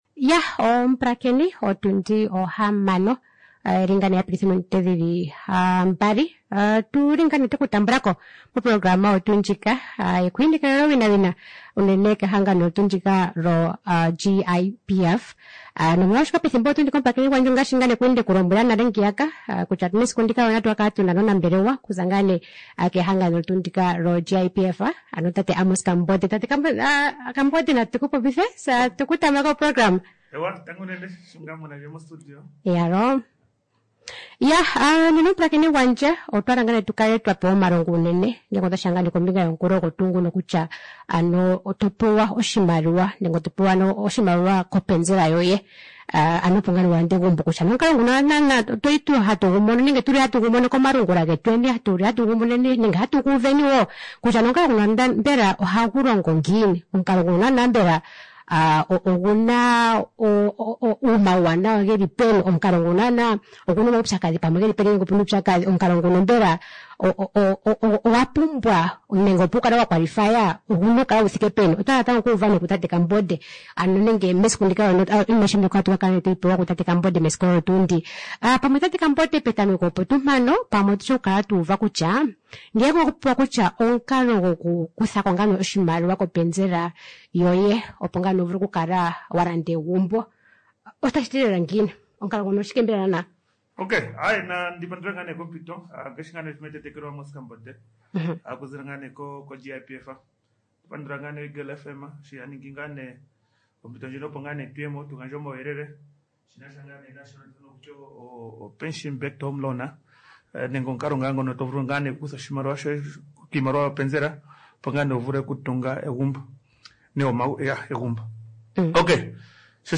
GIPF INTERVIEW 3 FEBRUARY 2026.mp3